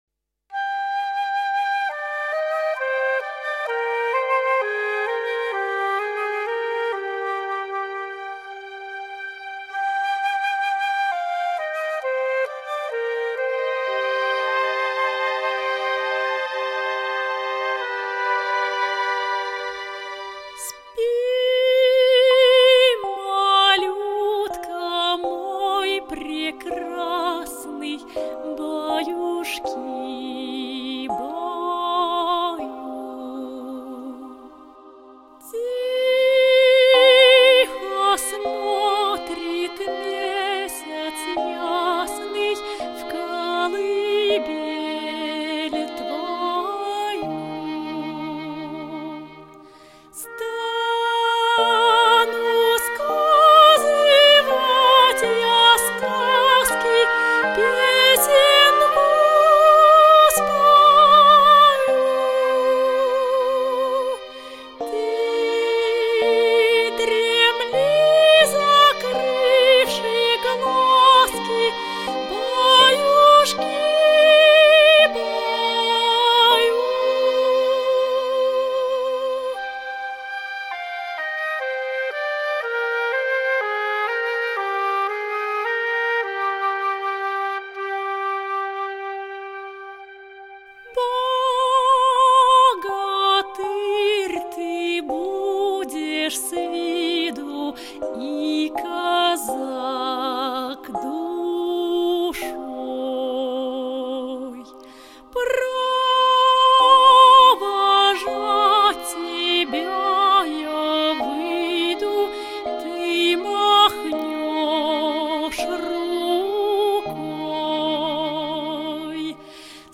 Аудиокнига Спи, усни | Библиотека аудиокниг